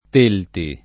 [ t % lti ]